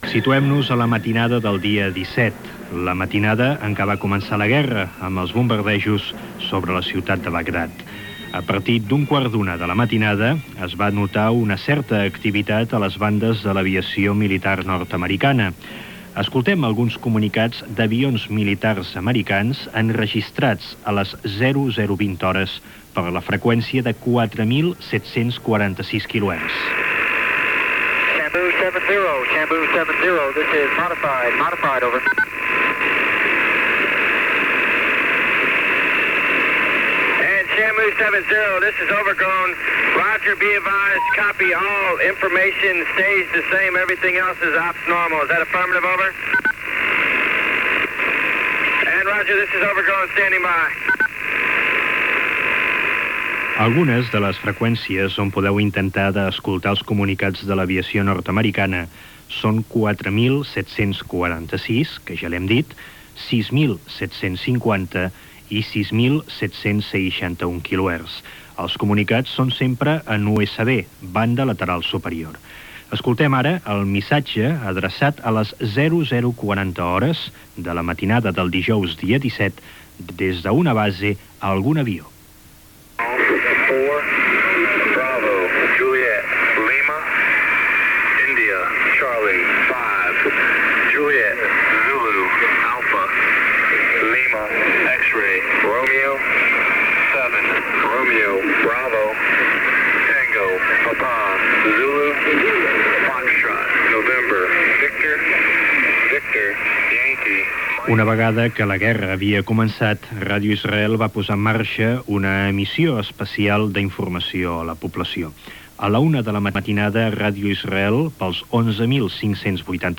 Fragments de les bandes de l'aviació militar nord-americana i de la Gran Bretanya i de les emissores de Ràdio Israel, Ràdio Bagdad, BBC, la Veu d'Amèrica, Ràdio Jordània, Ràdio Japó, Call Israel, i la Veu de l'Iraq Lliure.